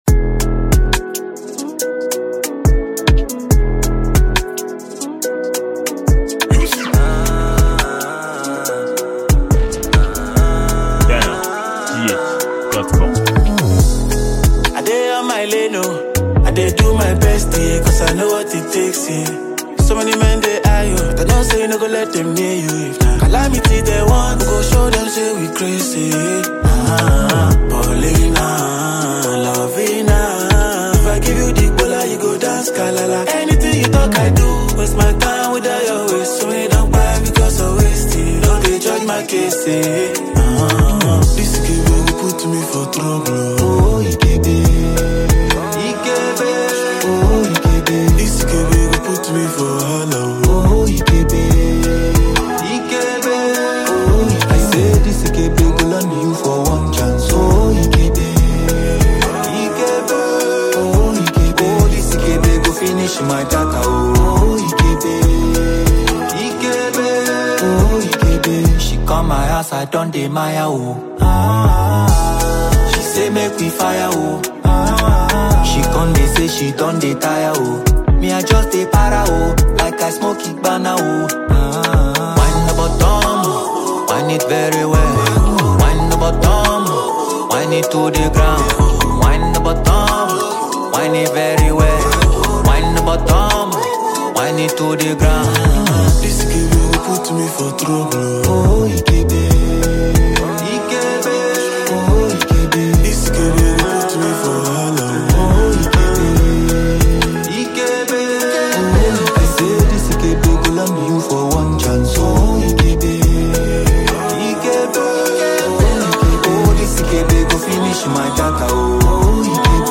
an afrobeat mp3 song for download.